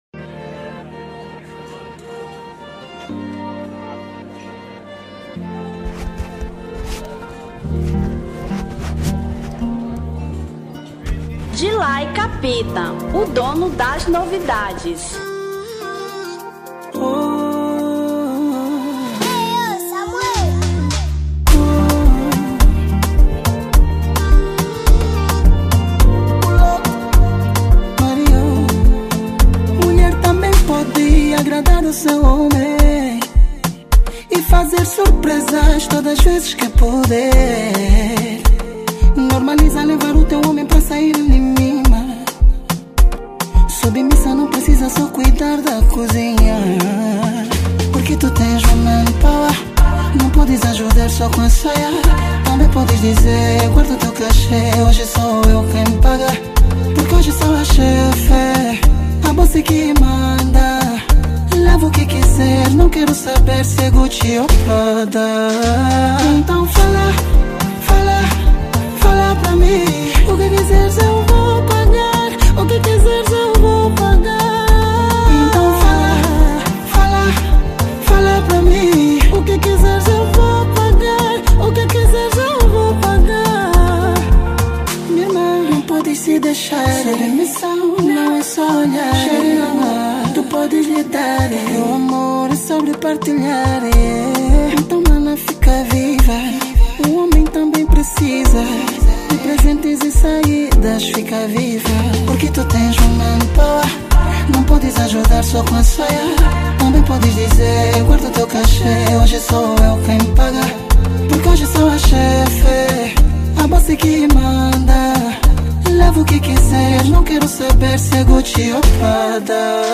Samba 2025